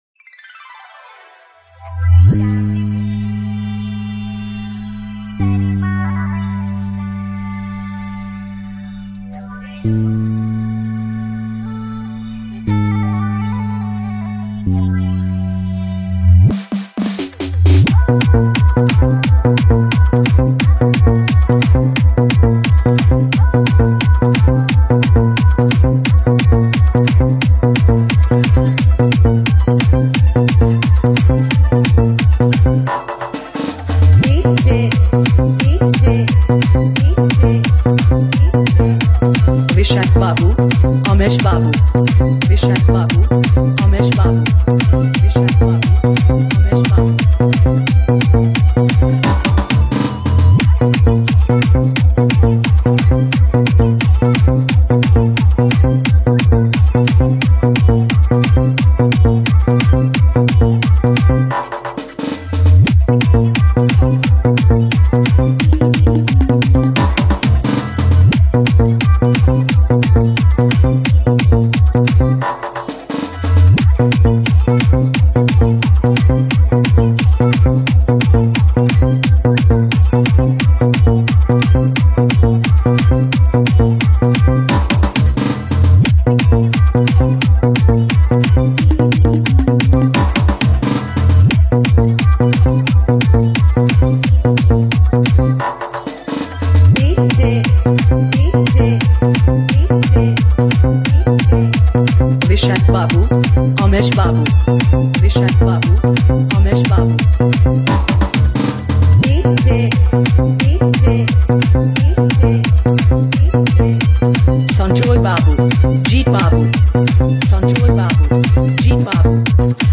Dj Remixer
New Nagpuri Dj Song 2025